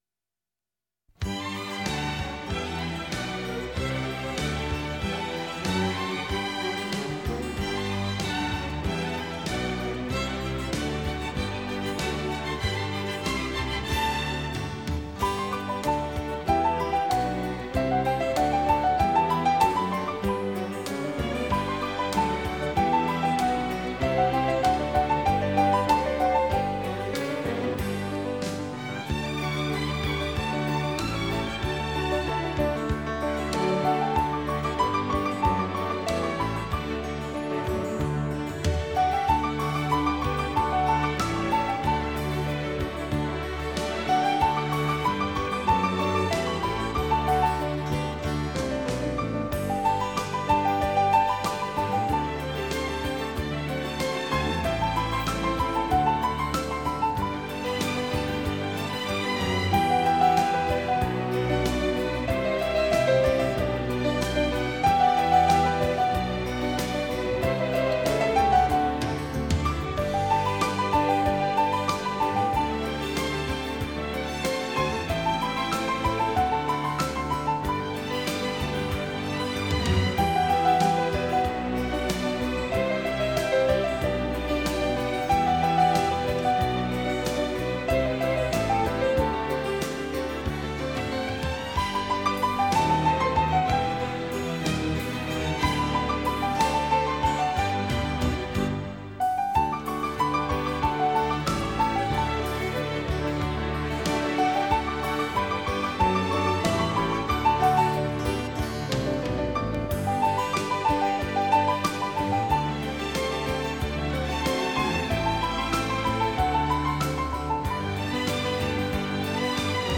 3周前 纯音乐 6